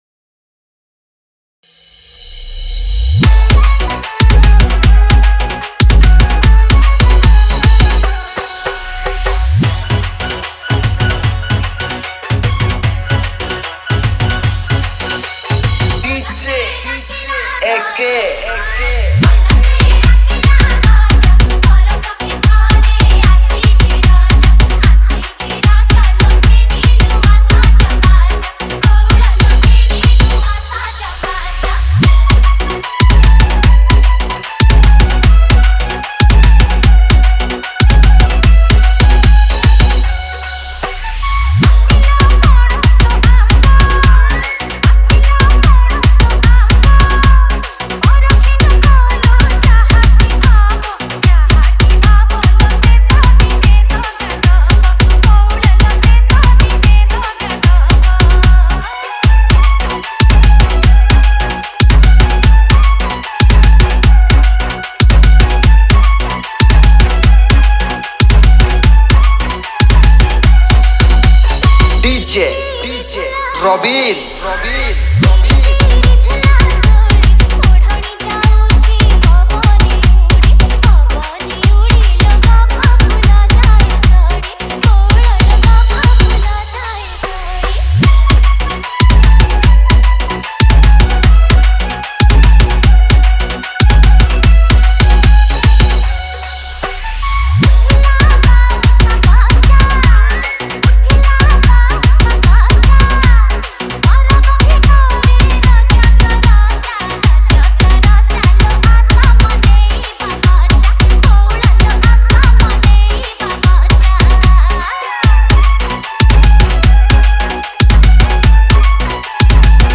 New Odia Dj Song 2024